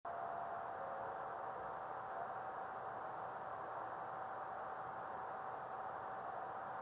Only extremely faint sound generated from this meteor.